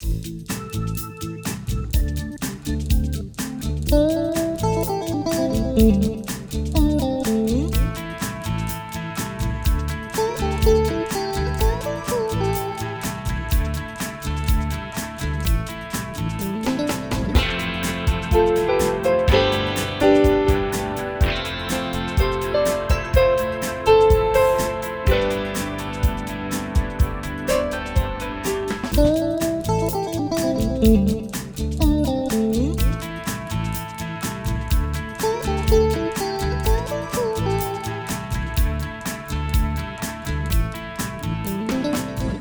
Pop rock 2 (bucle)
pop
melodía
repetitivo
rítmico
sintetizador